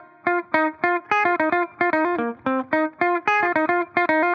Index of /musicradar/dusty-funk-samples/Guitar/110bpm